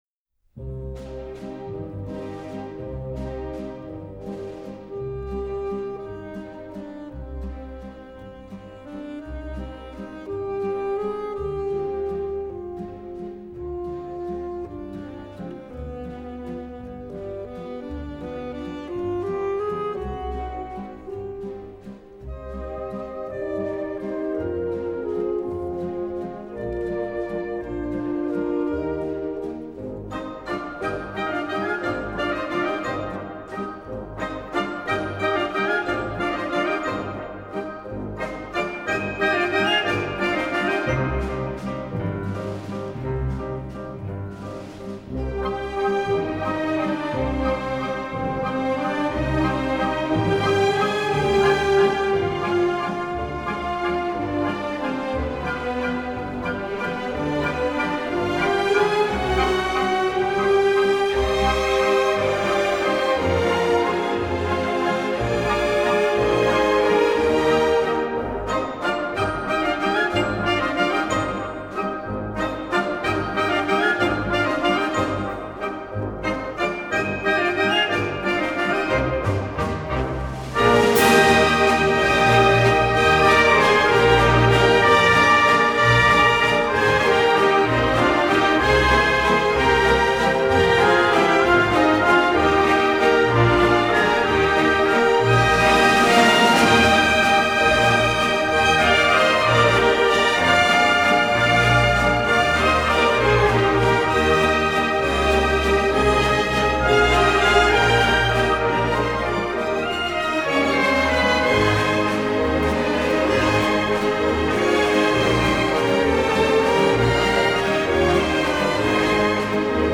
Es a la vez alegre y patética, puesto que se trata de una parodia.